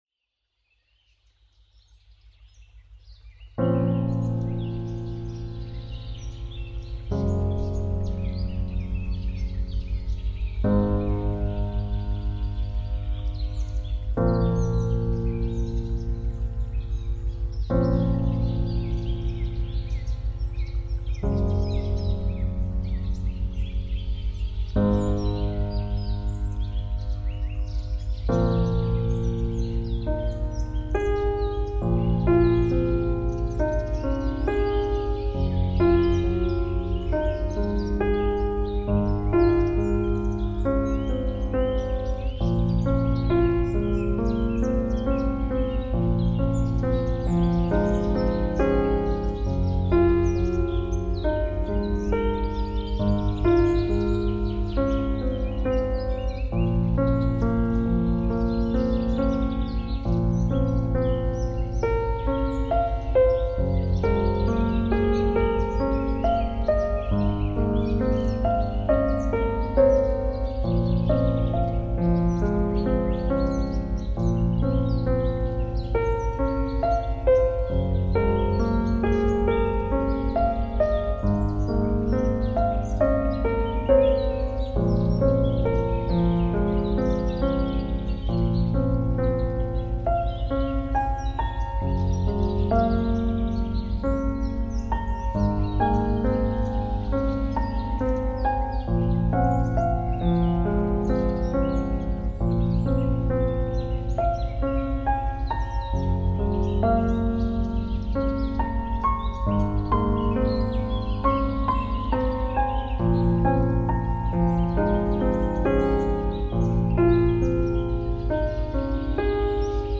• -1) Quatre fréquences spécifiques, sélectionnées en résonance avec les organes ciblés, pour stimuler leurs fonctions énergétiques et vibratoires.
• -2) Un accompagnement au piano accordé en 432 Hz (titre : Yūgen), fréquence naturelle réputée pour favoriser l’harmonie, la détente et l’alignement intérieur.
• -3) Une ambiance sonore inspirée de la nature (eau, vent, oiseaux…), qui enveloppe l’écoute dans une atmosphère apaisante et immersive.